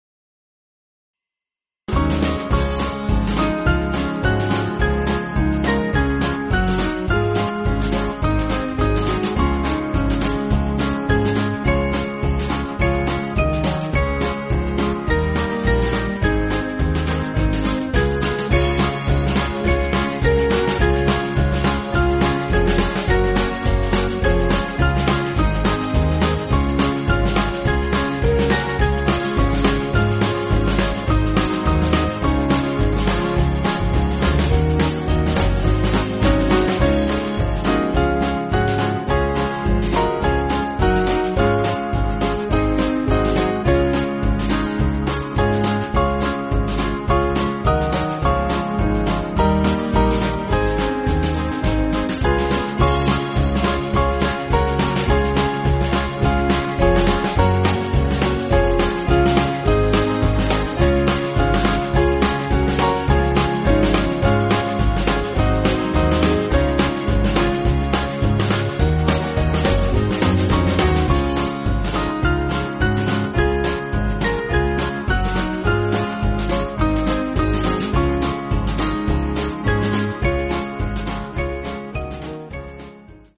Small Band
(CM)   5/Db-D